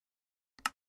bouton.mp3